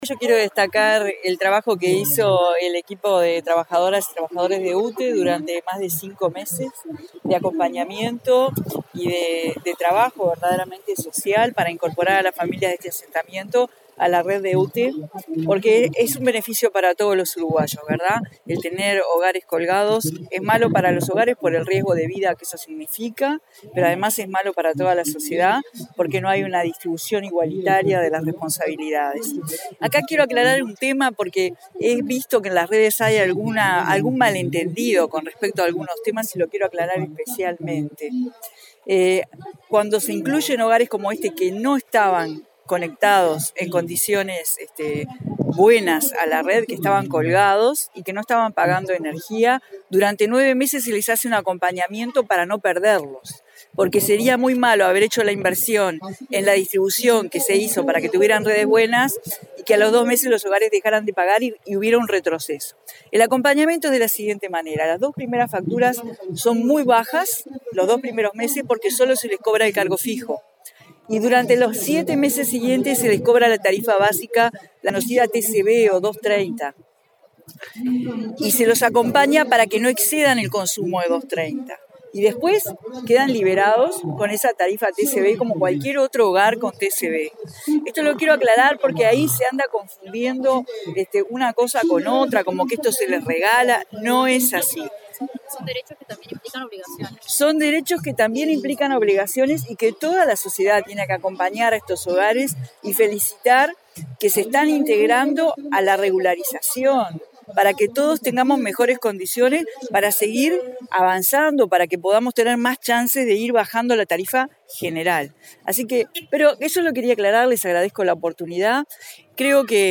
No es verdad que se les regale la energía, es un derecho que implica obligaciones, afirmó la ministra de Industria, Carolina Cosse, en referencia a la regularización del servicio eléctrico que realiza UTE. Explicó que cada hogar es acompañado durante 9 meses, tras la regularización del servicio, en el que se aplica cobro de tarifas diferenciales y asesoramiento en el uso.